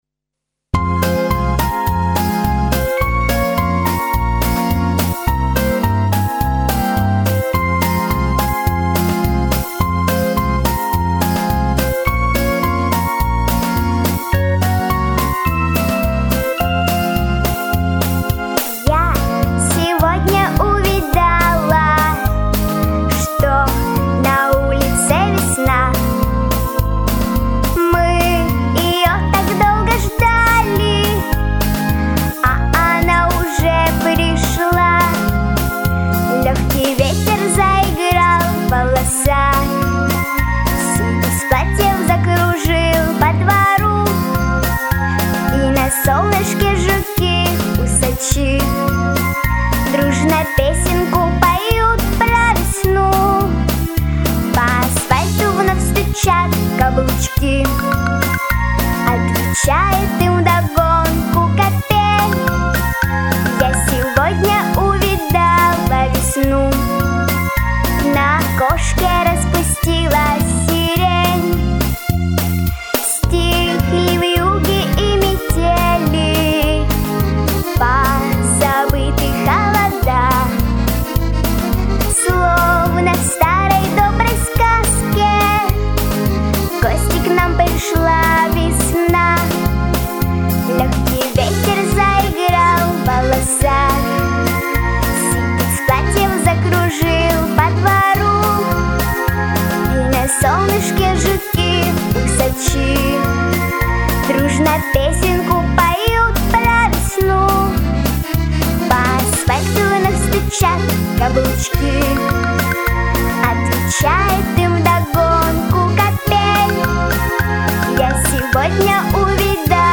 детская песня про весну